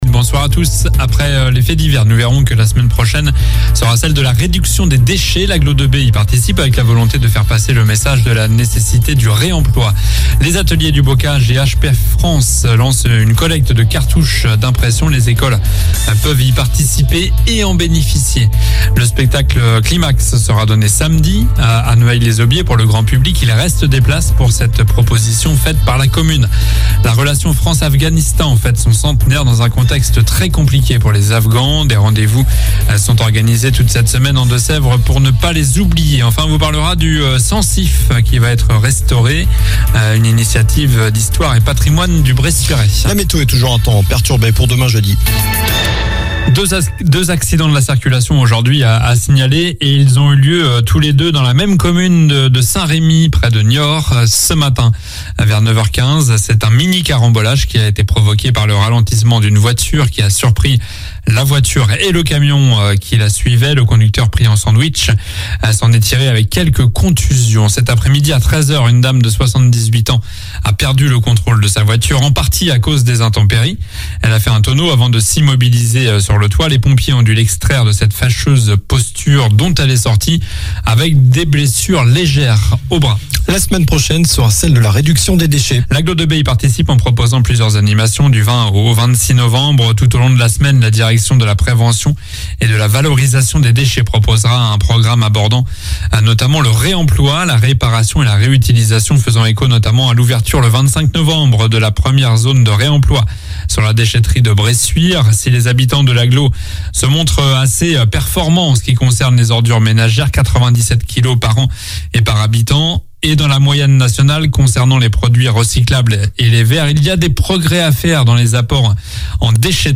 Journal du mercredi 16 novembre (soir)